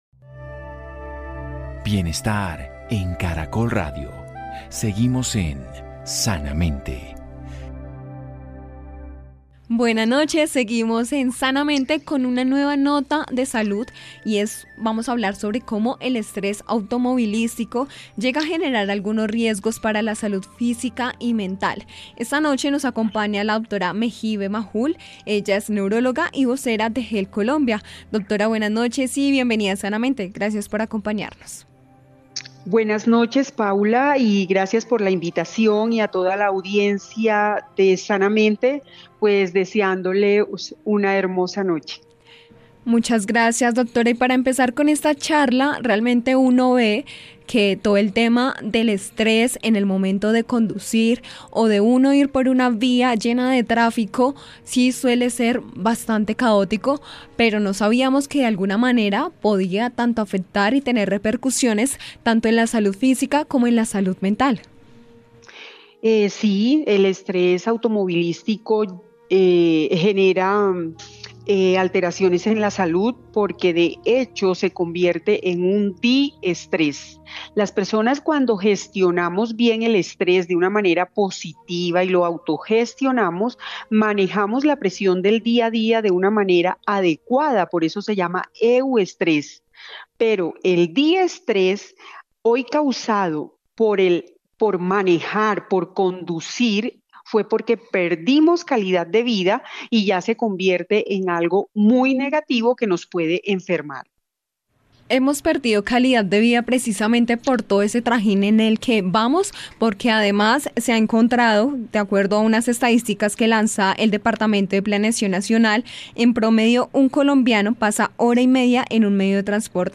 Una médica neuróloga conversa en Sanamente para brindar recomendaciones sobre cómo reducir el estrés automovilístico.